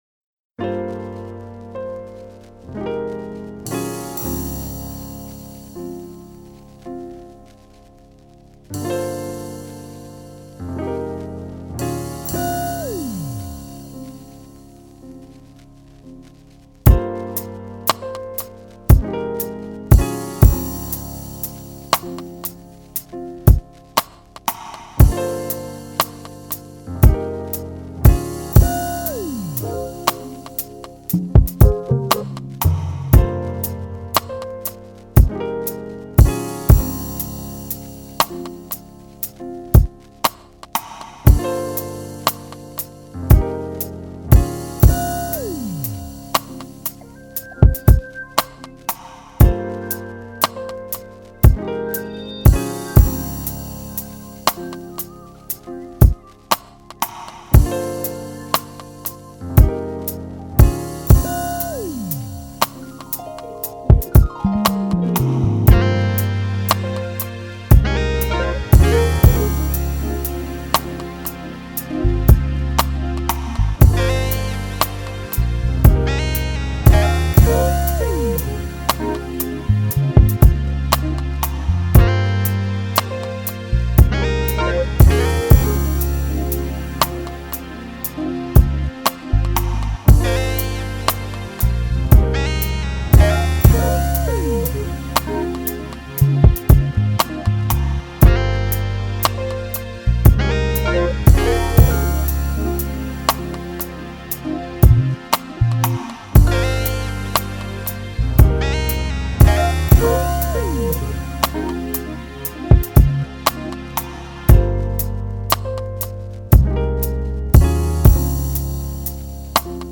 R&B Throwback Instrumentals